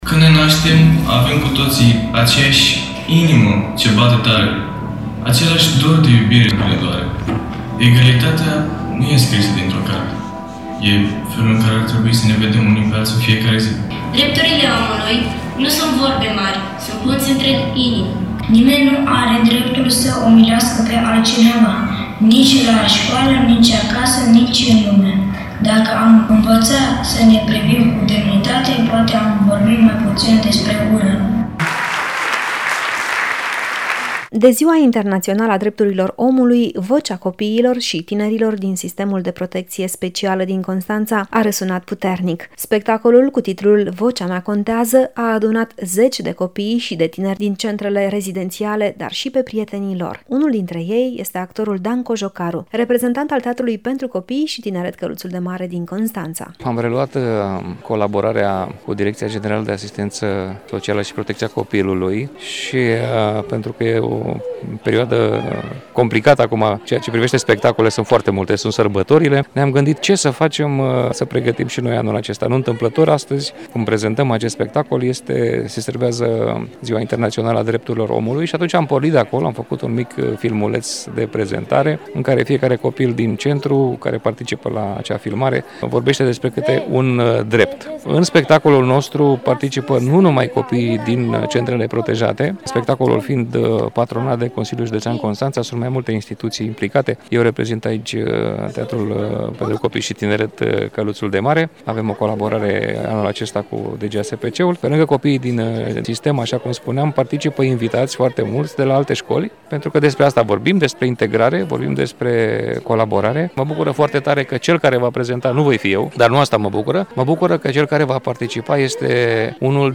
Spectacolul a avut loc pe scena Centrului Multifuncțional Educativ pentru Tineret „Jean Constantin”.
De Ziua Internațională a Drepturilor Omului, copiii și tinerii din sistemul de protecție specială din Constanța au susținut un spectacol emoționant numit  “Vocea mea contează”.
Zeci de adolescenți au urcat pe scenă, au cântat și au transmis mesaje puternice despre drepturile fundamentale ale fiecărei persoane, în special ale copiilor aflați în situații de vulnerabilitate.
Urmează un reportaj